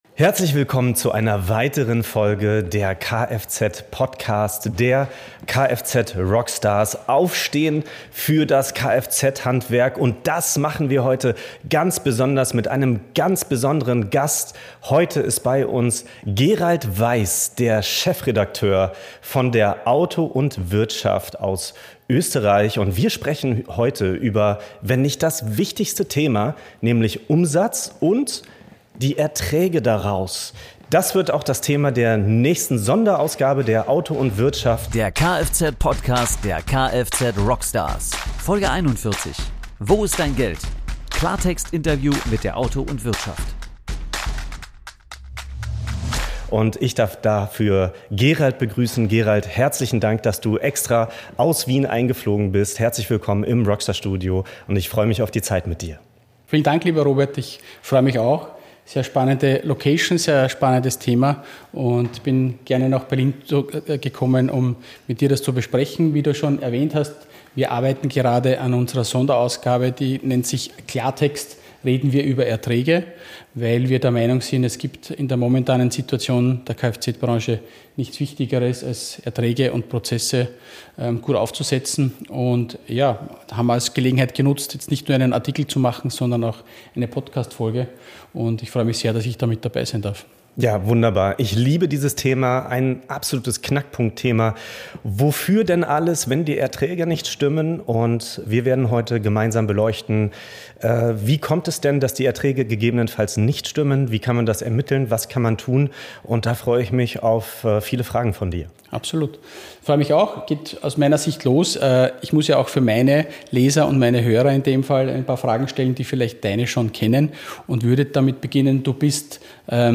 #041 Wo ist dein Geld? Klartextinterview mit der AUTO & Wirtschaft